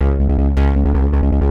hous-tec / 160bpm / bass
wwbass-2.wav